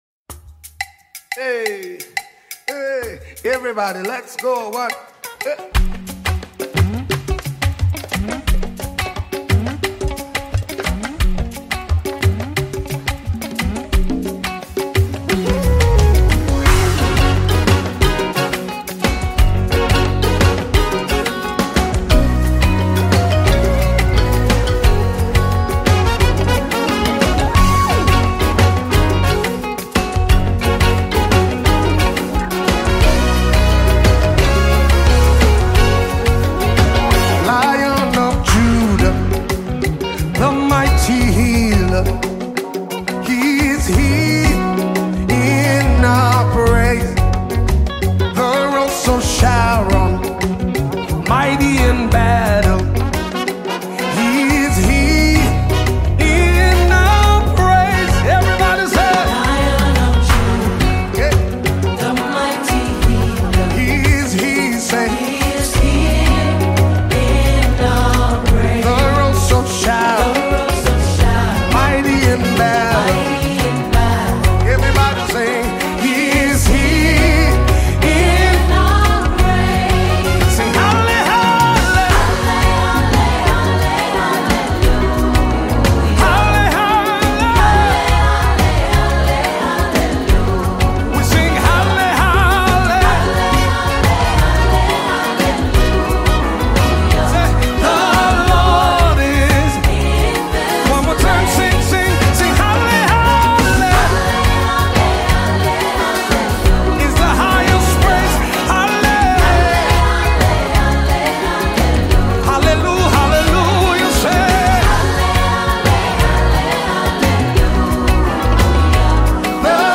This is a New Single by Ghanaian Gospel Music Minister